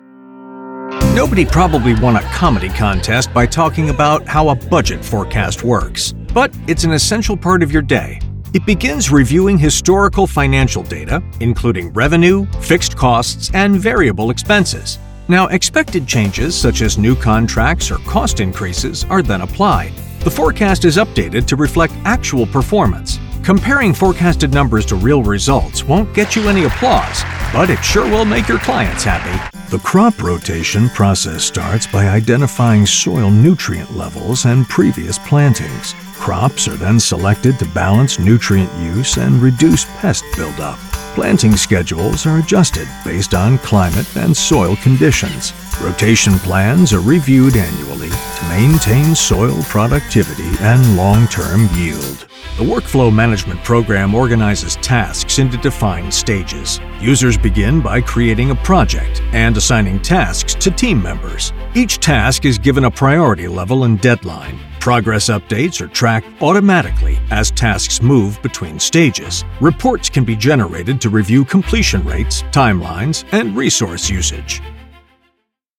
Vídeos explicativos
His voice has been described as Articulately Conversational.
Neumann TLM-103
Custom designed broadcast quality Whisper Room
Mediana edad